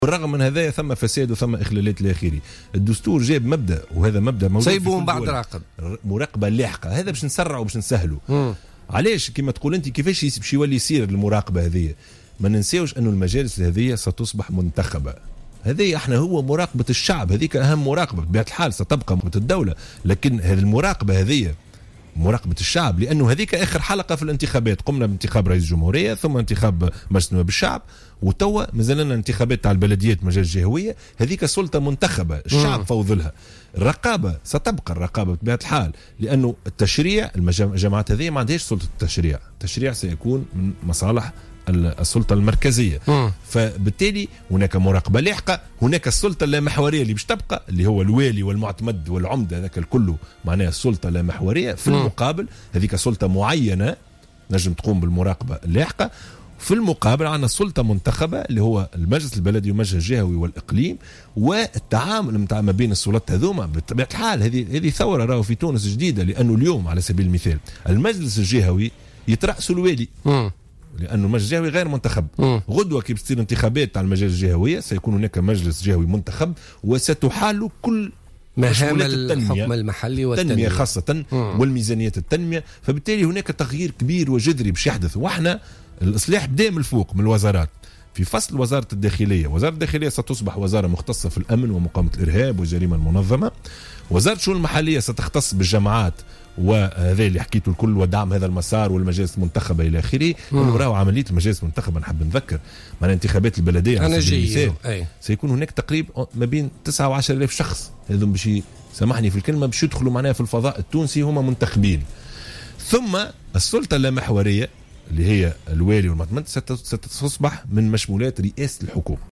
قال وزير الشؤون المحلية يوسف الشاهد في تصريح للجوهرة أف أم في برنامج بوليتكا لليوم الخميس 14 أفريل 2016 إن الوزارة ستعمل على تفعيل مسار اللامركزية رغم التعقيدات والعوائق باعتبار أن تونس دولة مركزية بامتياز.